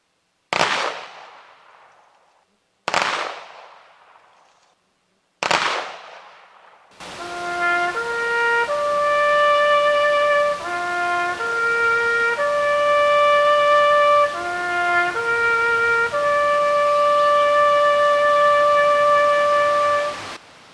FORT RILEY – A Memorial Day ceremony honored fallen soldiers Monday at the Fort Riley post cemetery.
TAPS.wav